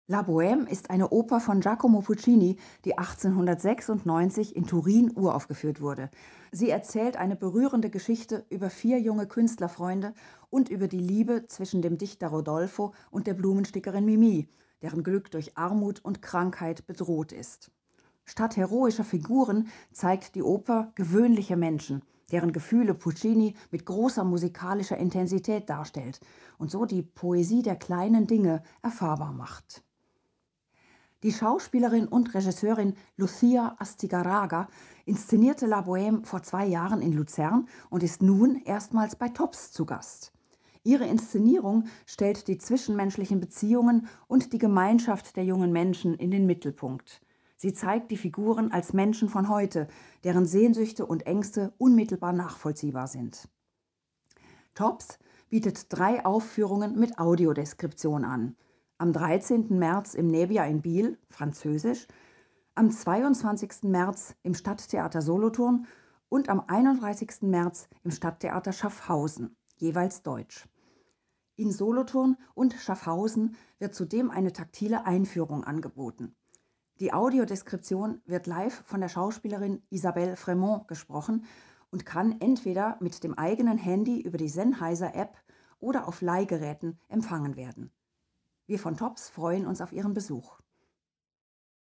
Audiodeskription & taktile Einführung
→ Audiotrailer «La Bohème»
La_Bohème_Teaser_Audiodeskription_D.mp3